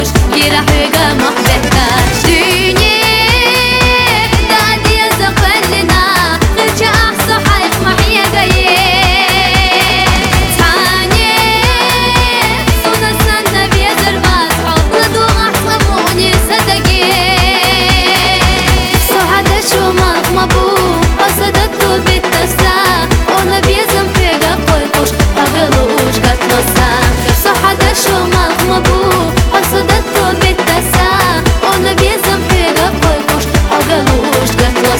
Жанр: Русский поп / Русский рэп / Русский шансон / Русские